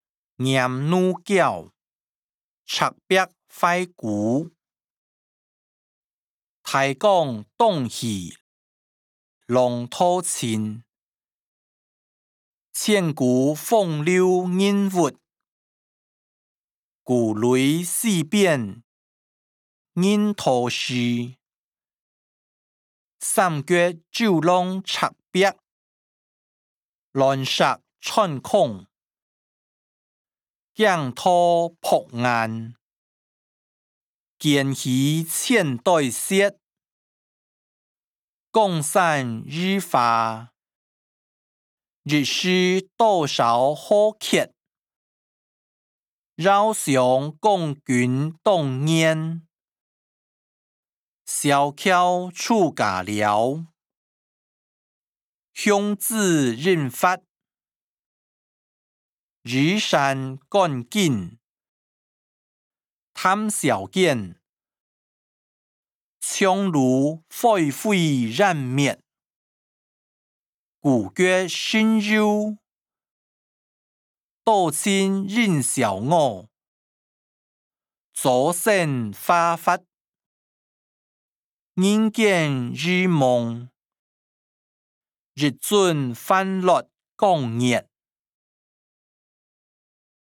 詞、曲-念奴嬌•赤壁懷古音檔(海陸腔)